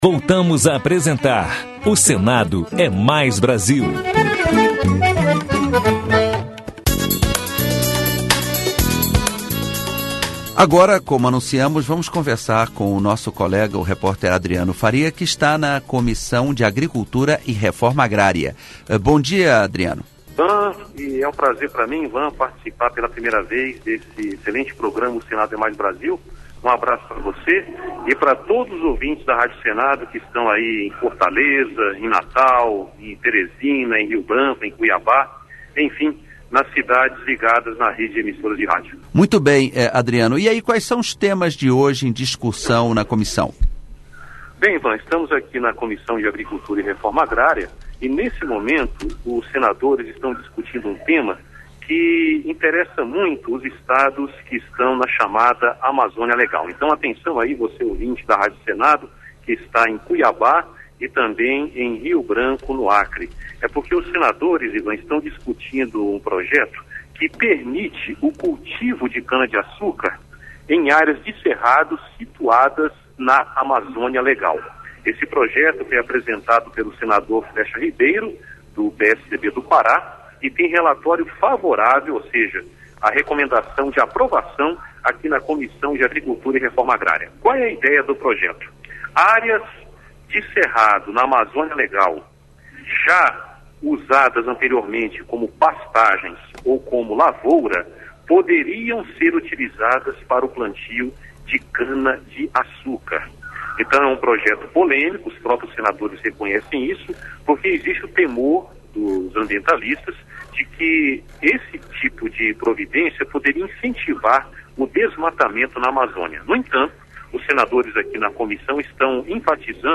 Entrevista com o senador Walter Pinheiro (PT-BA)